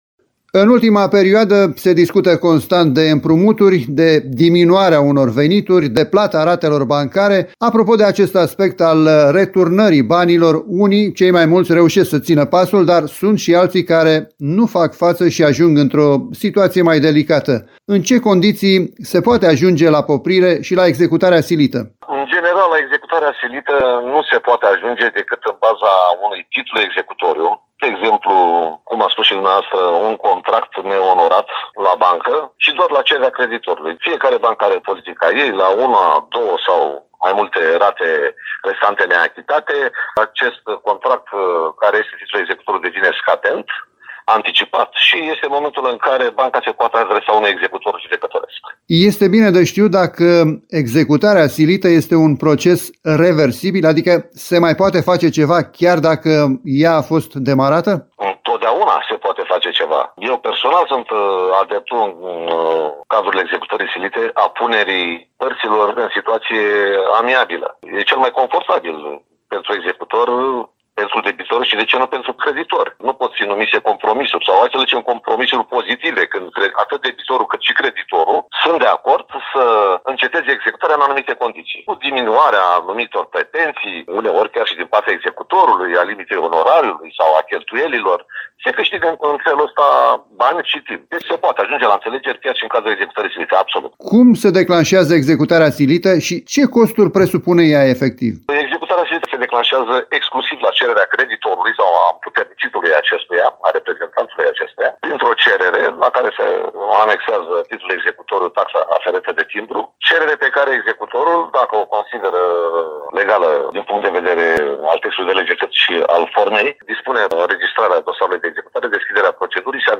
executor judecătoresc.